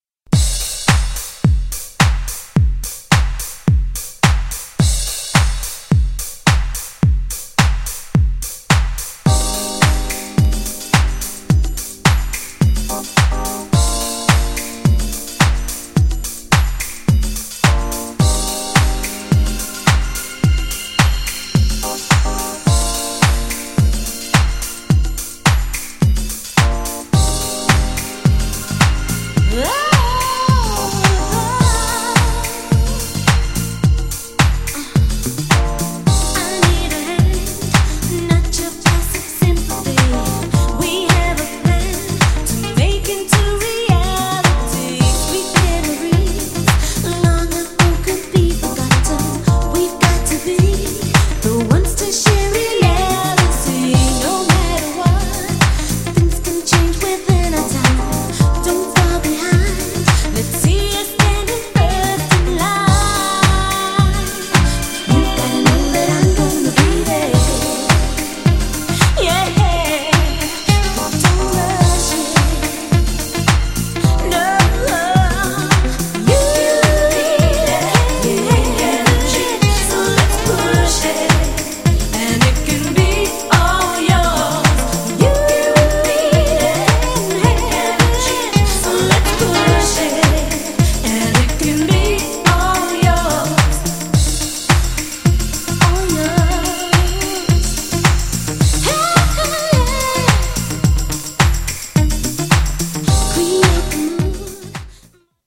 めちゃDEEEP!!
GENRE House
BPM 106〜110BPM